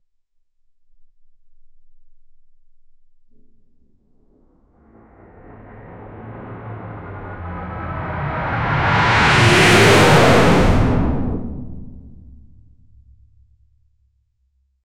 Dramatic-effects Sound Effects - Free AI Generator & Downloads
a-fast-and-dynamic-dolly-meo5crth.wav